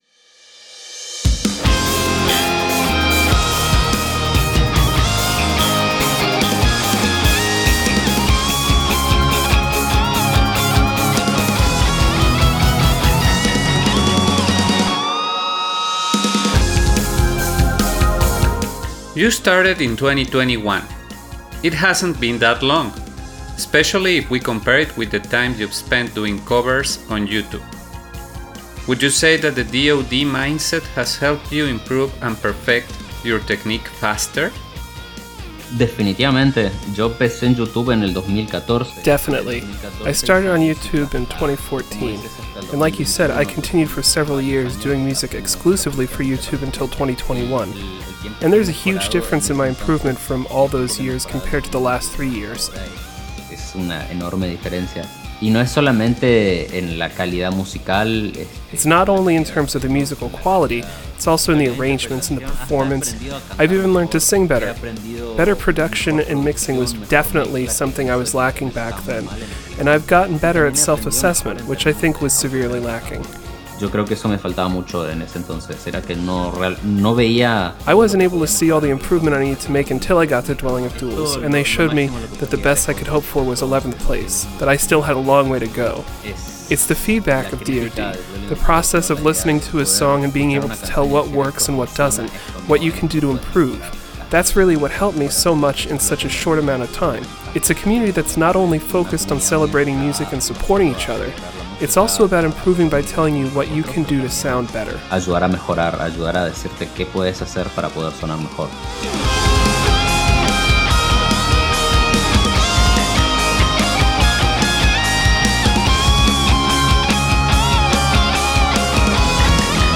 It was only appropriate that the music for his interview was DB related, since we discuss both his origins and evolution as a musician.